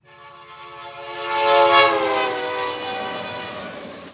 Silbido del paso de un tren. Efecto doppler.
Efecto Doppler
tren
ráfaga
silbido
Sonidos: Transportes